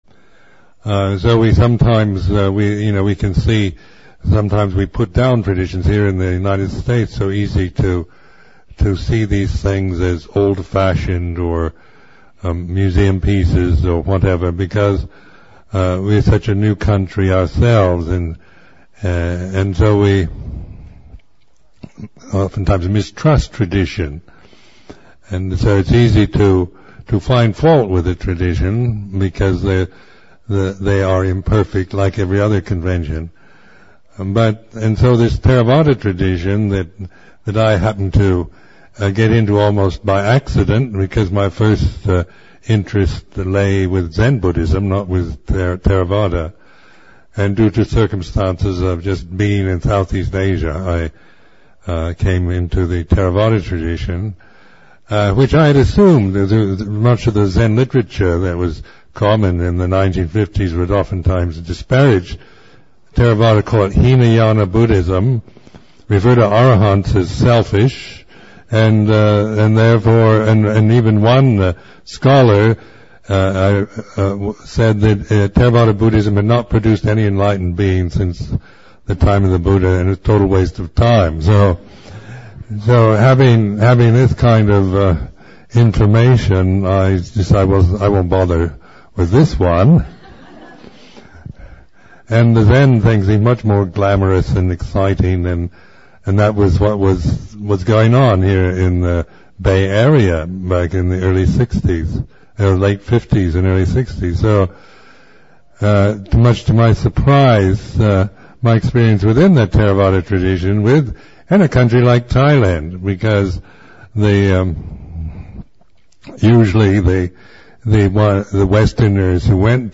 2. Reflection by Ajahn Sumedho: The unexpected value of traditional Buddhist forms.